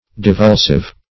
Divulsive \Di*vul"sive\